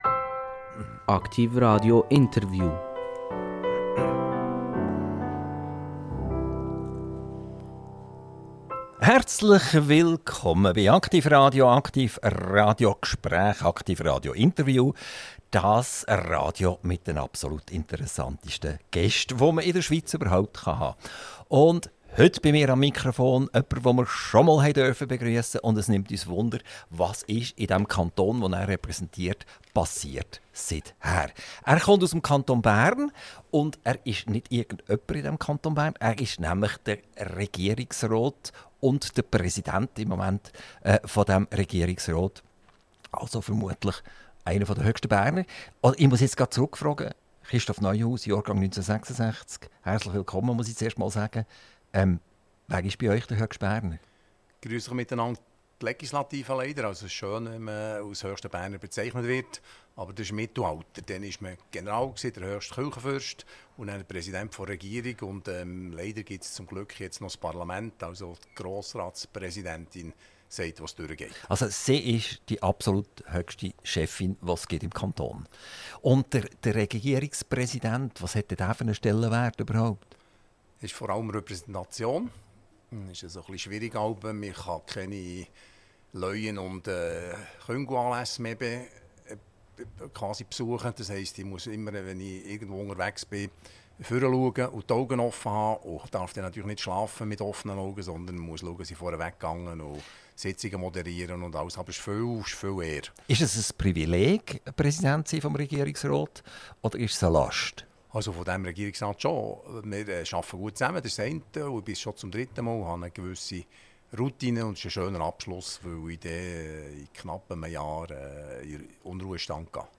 INTERVIEW - Christoph Neuhaus - 27.06.2025 ~ AKTIV RADIO Podcast